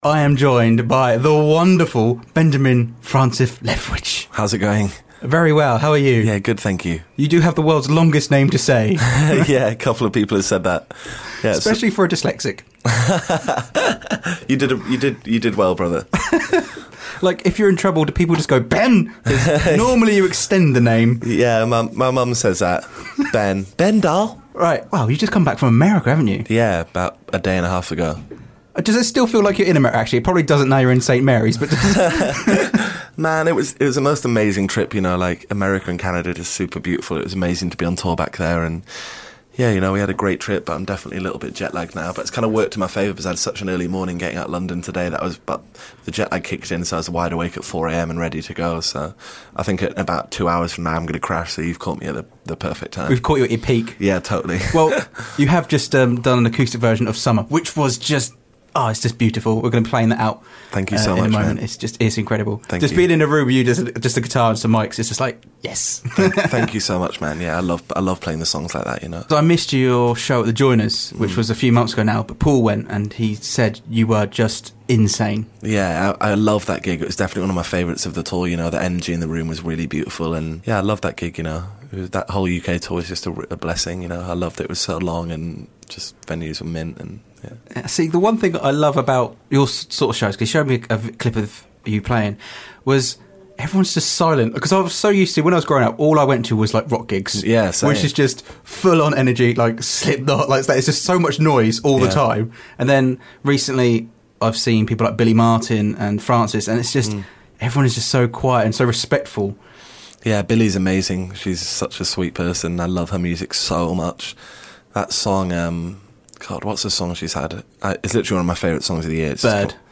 Benjamin Francis Leftwich interview Aug 2016